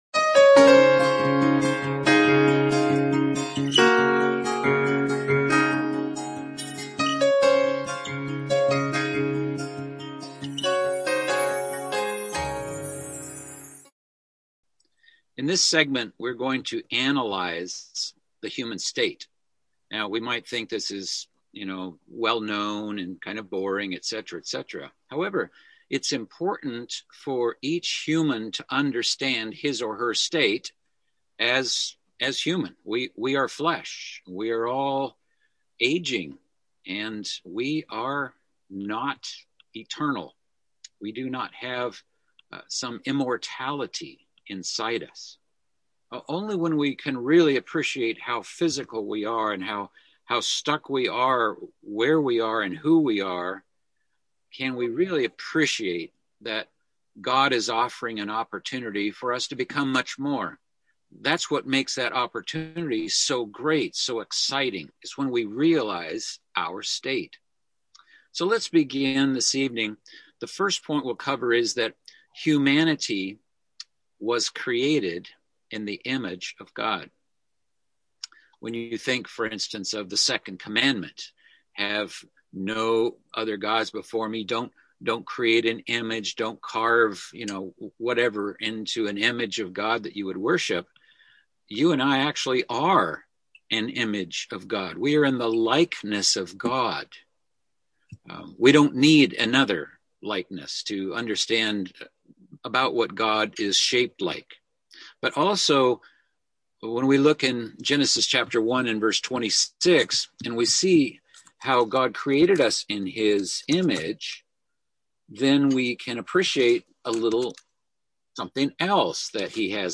Fundamental Biblical Beliefs - Bible Study